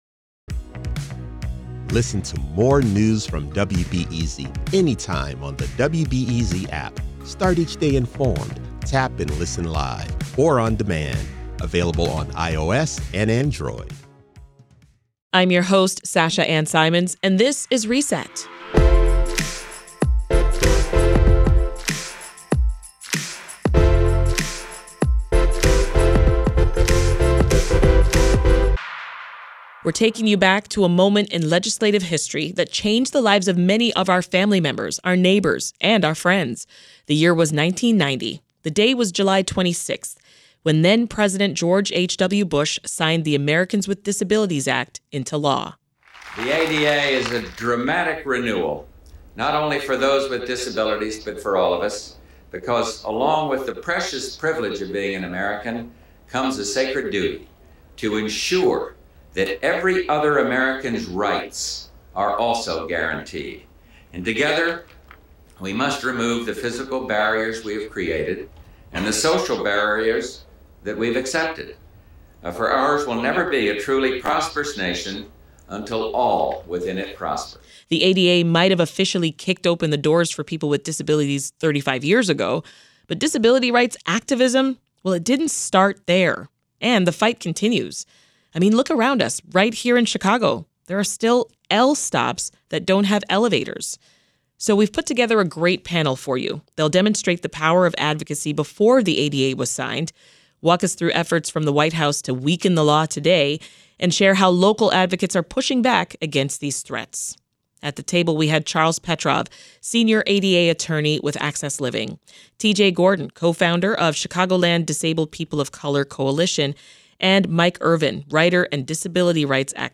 Reset talks about disability advocacy before and after the ADA. We also dig into recent efforts to weaken it and how the community continues to fight. Our panel: